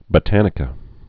(bə-tănĭ-kə)